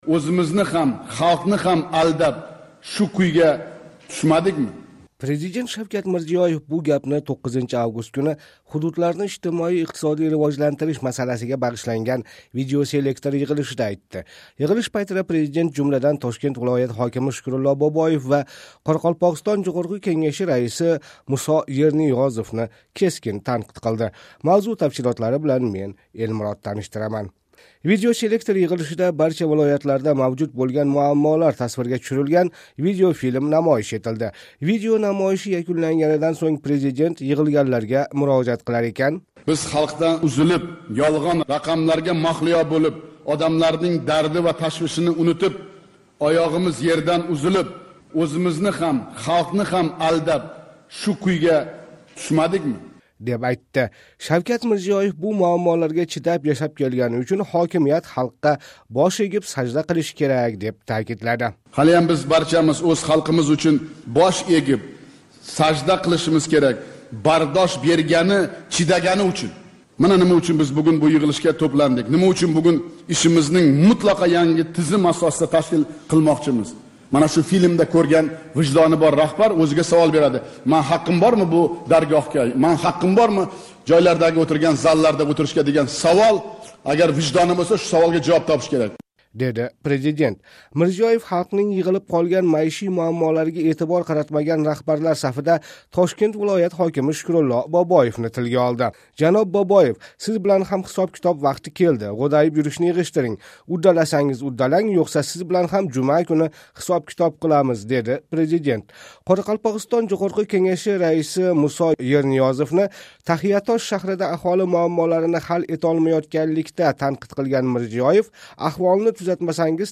Президент Шавкат Мирзиев бу гапни 9 август куни ҳудудларни ижтимоий-иқтисодий ривожлантириш масаласига бағишланган видеоселектор йиғилишида айтди.
Видео намойиши якунланганидан сўнг, президент йиғилганларга мурожаат қилар экан: